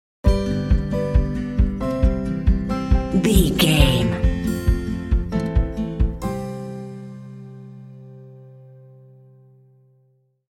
Fun and cheerful indie track with bells and “hey” shots.
Uplifting
Ionian/Major
cheerful/happy
playful
acoustic guitar
piano
drums
alternative rock
contemporary underscore